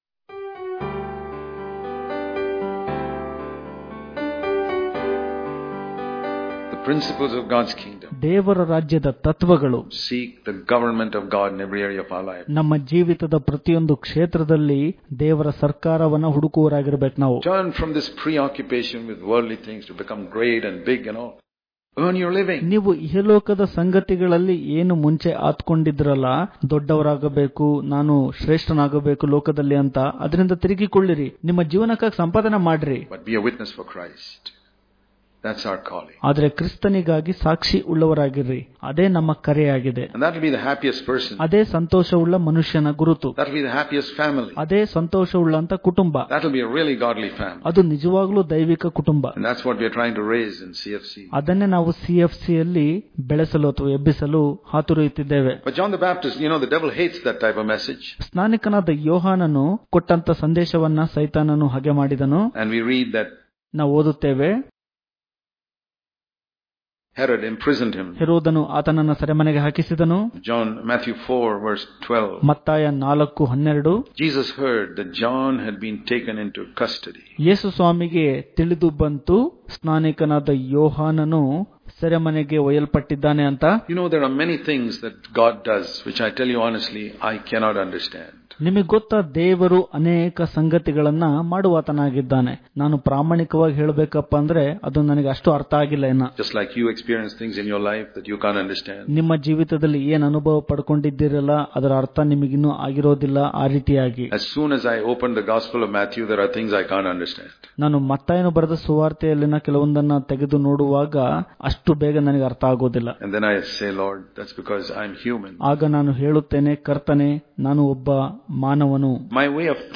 August 26 | Kannada Daily Devotion | God's Ways Are Not Our Ways Daily Devotions